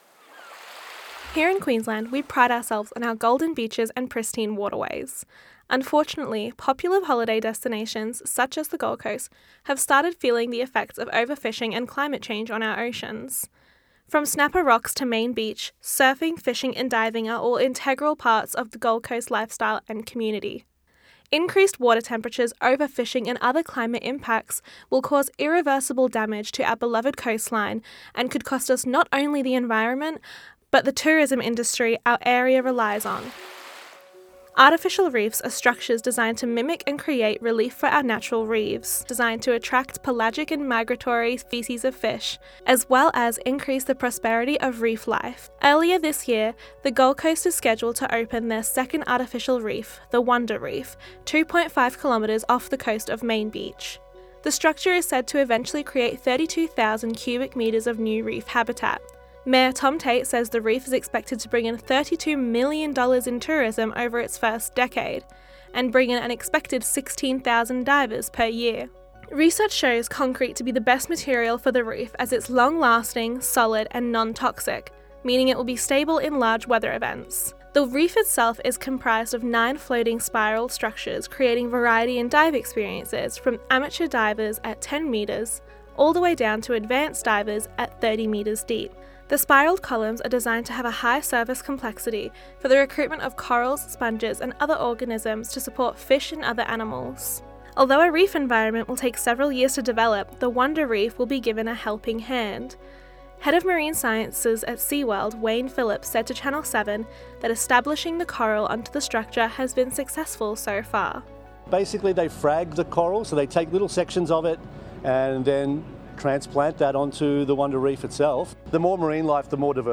marine scientists
seasoned local fisherman